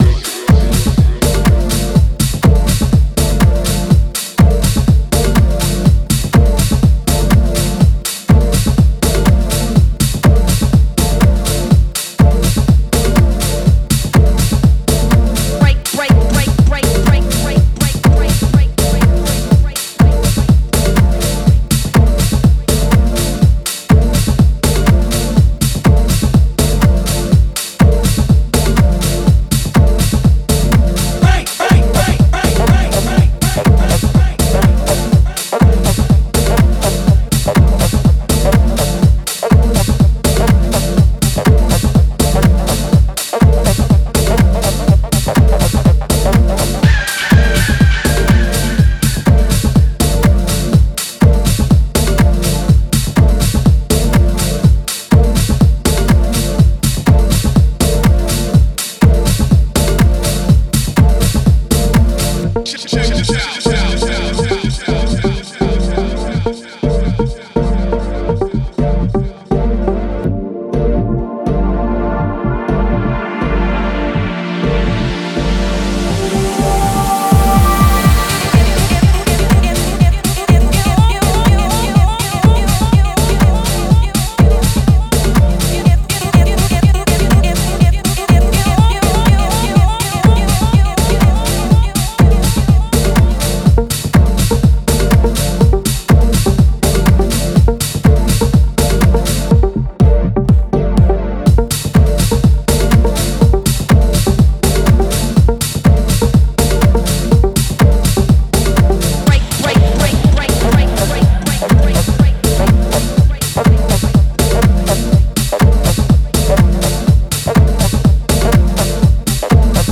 ジャンル(スタイル) HOUSE